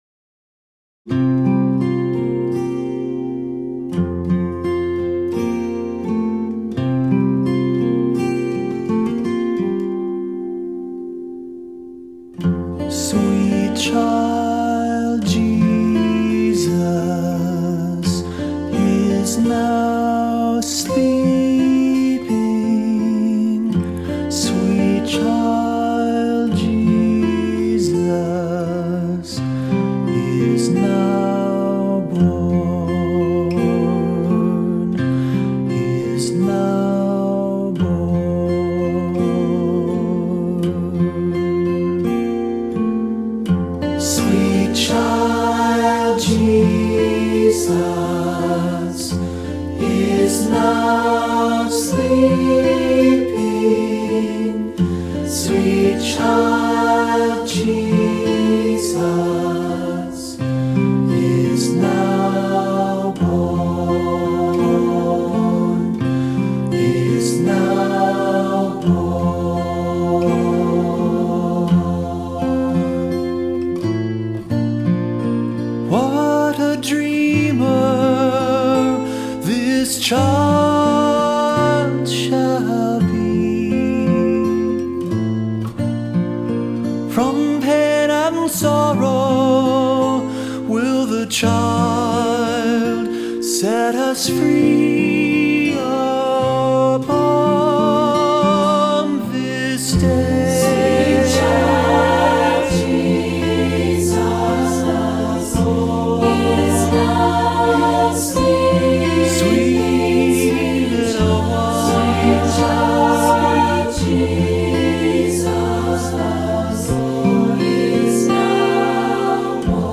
Voicing: Assembly